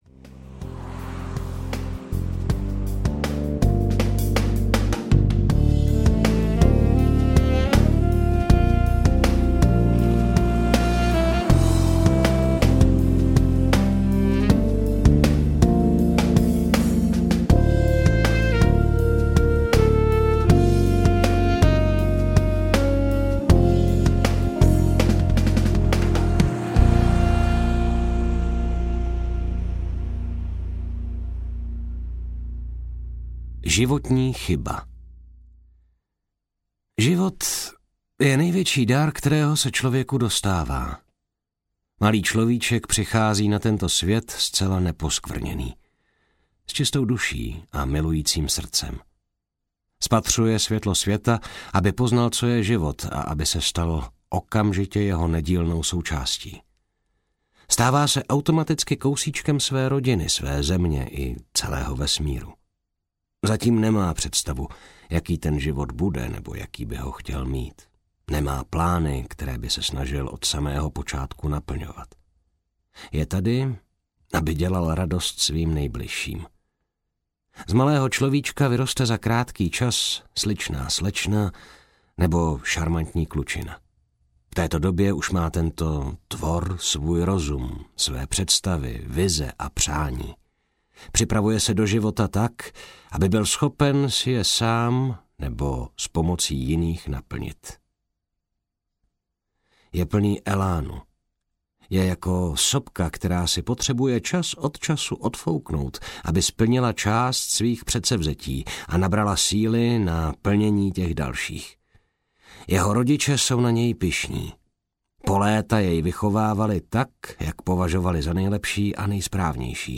Zabil jsem… audiokniha
Ukázka z knihy